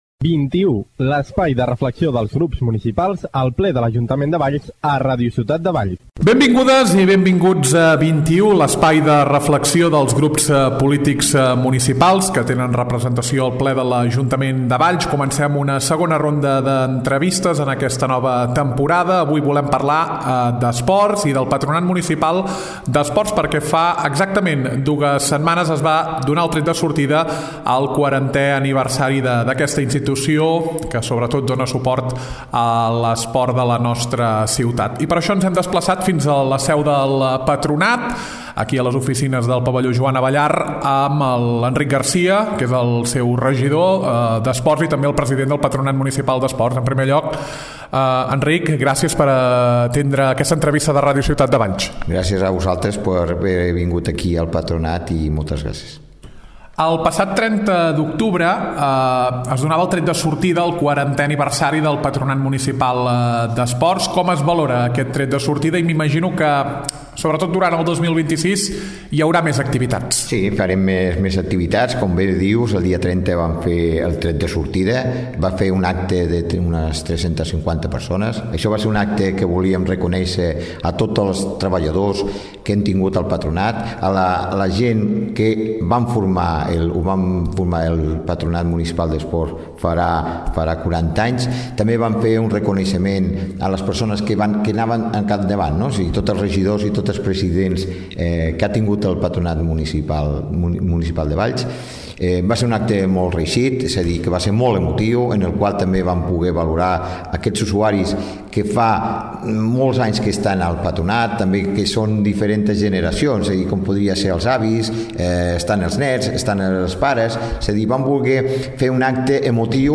Entrevista a Enric Garcia, regidor d’Esports.